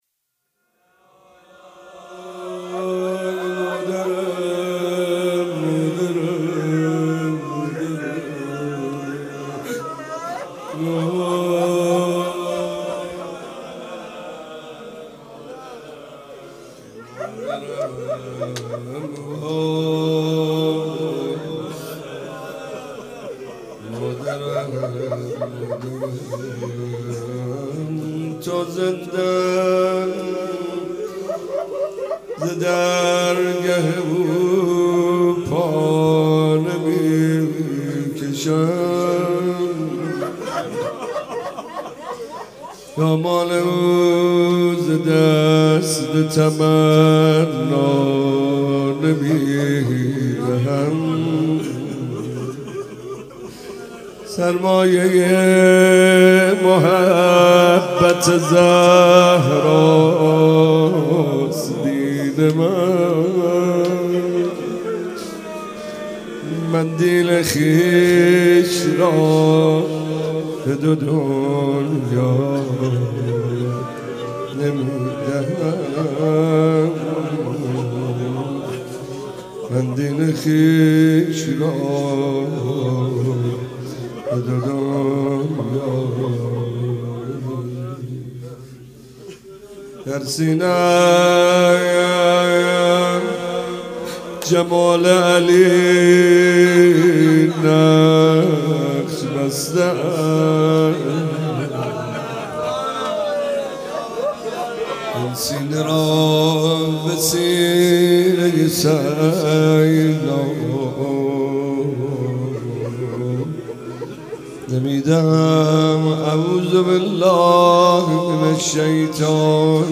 مداح : محمدرضا طاهری قالب : روضه